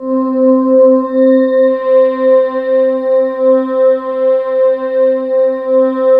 Index of /90_sSampleCDs/USB Soundscan vol.13 - Ethereal Atmosphere [AKAI] 1CD/Partition D/07-ANAPLASS